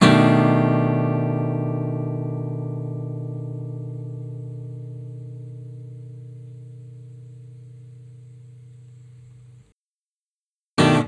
08 Jazzy Four.wav